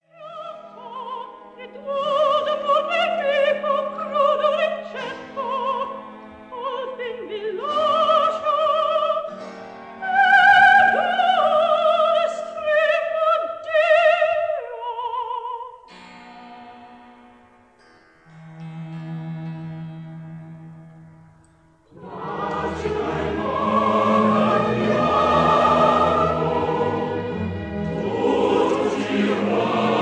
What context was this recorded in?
Recorded in Abbey Road Studio No. 1, London